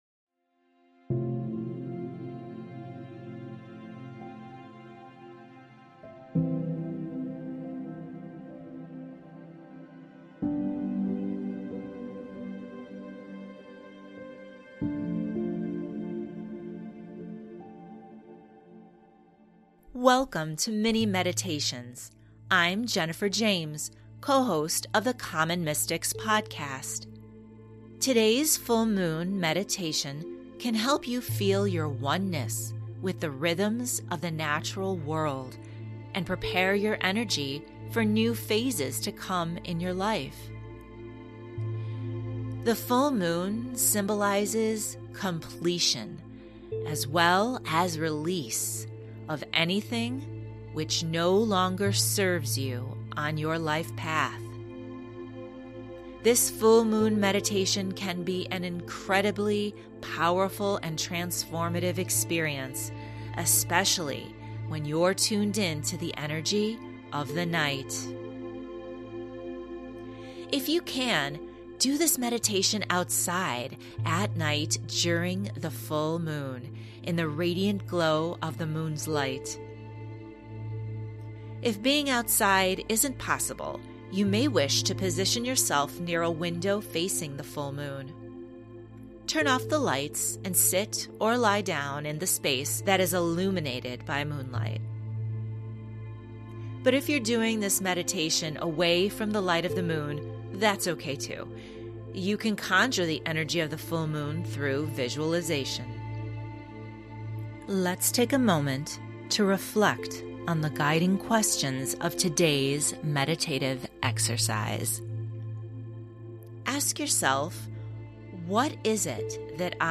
Common Mystics' Mini Meditation for the Full Moon